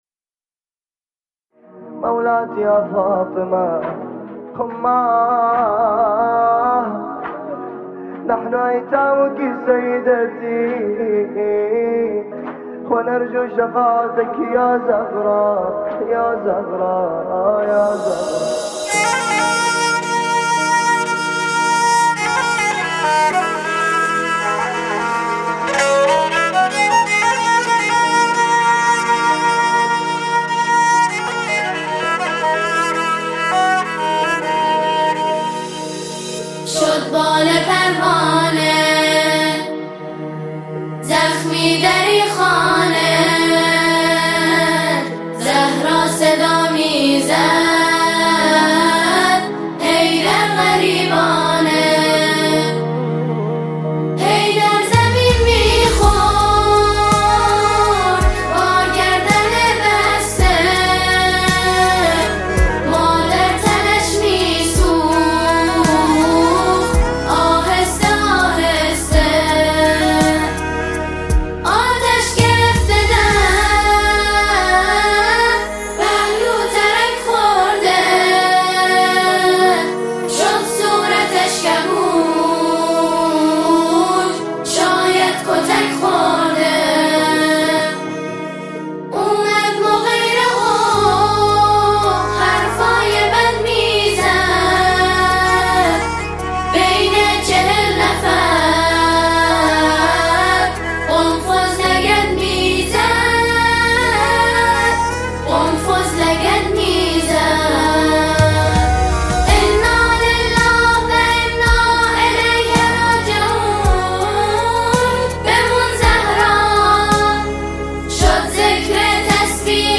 اثری عزادارانه و پر از اندوه است
فضای موسیقی و شعر، مملو از بغض و سوگ است؛
ژانر: سرود